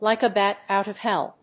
アクセントlìke a bát òut of héll